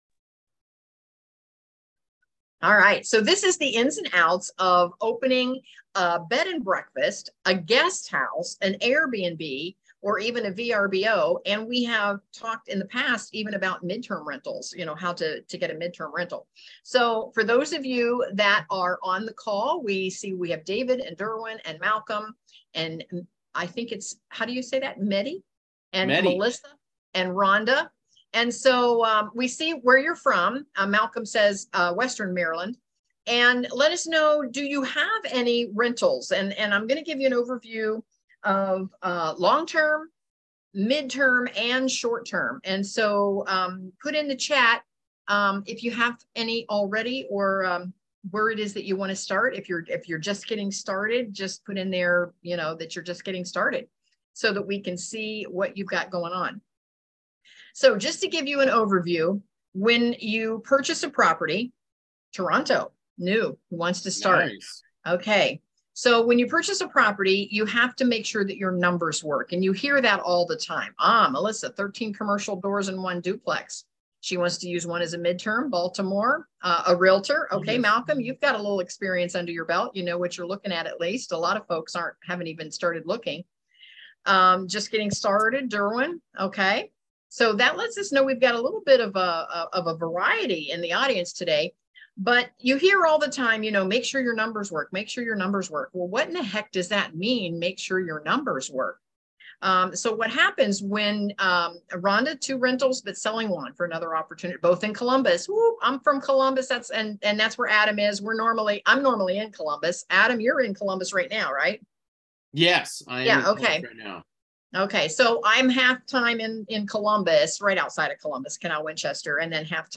Listen in to hear her unique way of advertising her short-term rentals and why this is so successful. You won't want to miss listening to this interview!